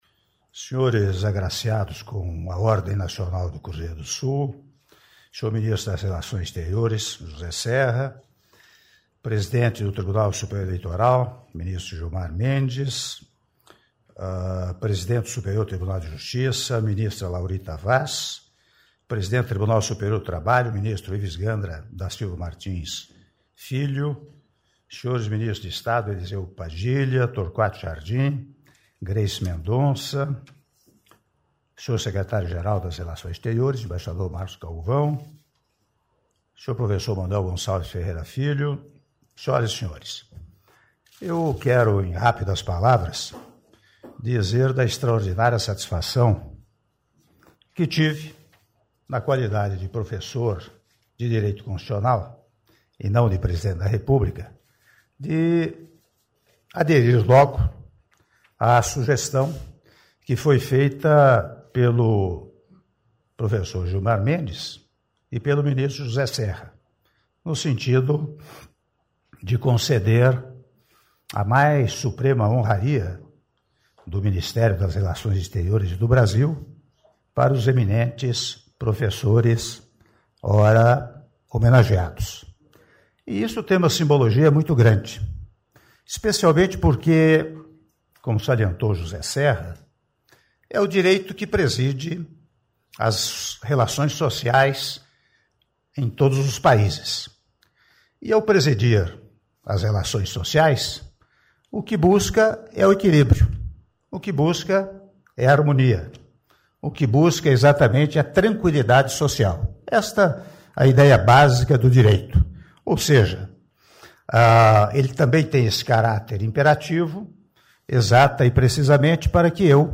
Áudio do discurso do Presidente da República, Michel Temer, durante cerimônia de entrega de condecorações da Ordem Nacional do Cruzeiro do Sul a juristas estrangeiros - Brasília/DF (06min08s)